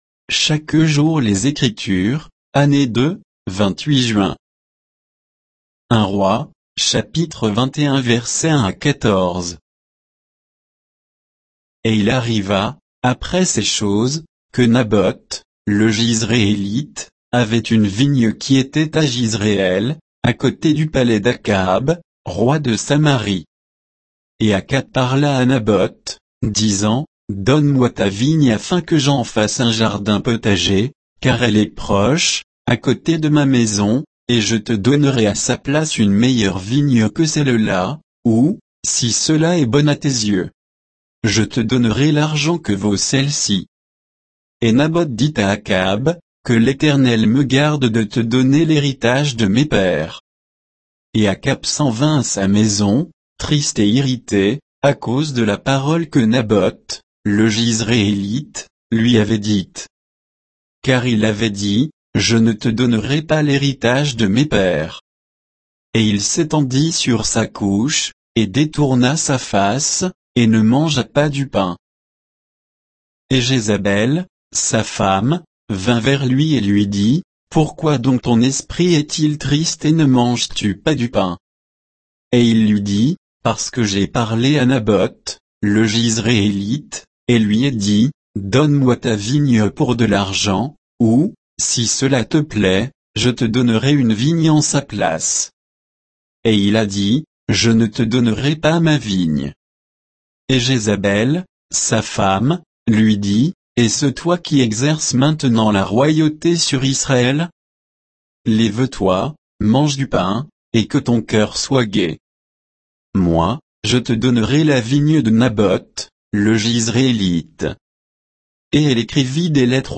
Méditation quoditienne de Chaque jour les Écritures sur 1 Rois 21